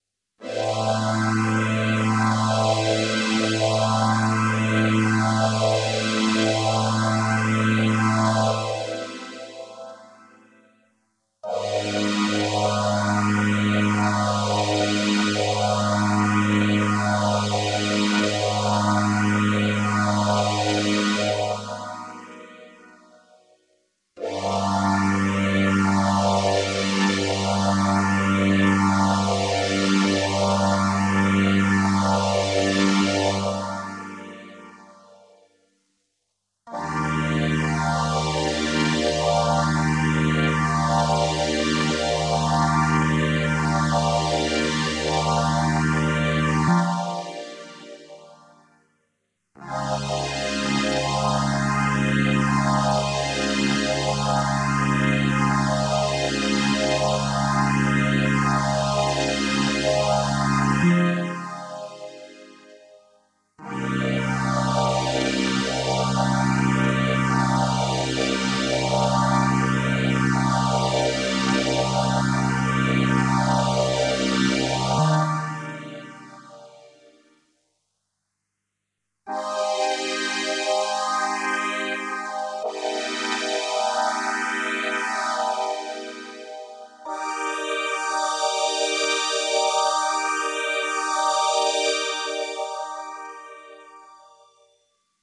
描述：一些和弦在Arp Solina弦乐合奏中演奏，通过Electro Harmonix Small Stone Phaser踏板。
标签： 字符串 类似物 合成器 索利纳 硬件 葡萄酒 和弦 移相器 阿普
声道立体声